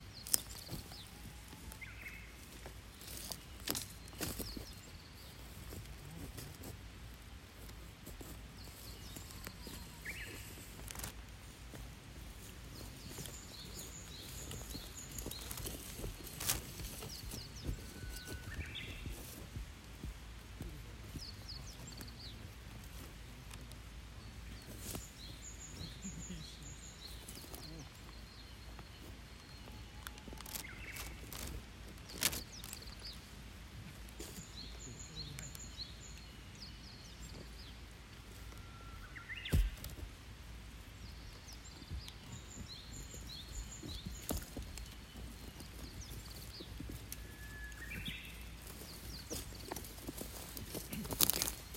森の音をお届け！
2024年の植樹日はとても天気が良く、カンキョーダイナリーの森も虫や鳥の鳴き声が心地よく響いていました。2024年はスタッフが植樹作業中に録音した「カンキョーダイナリーの森で聞こえる実際の音」をお届け！
kankyodainari_forest.mp3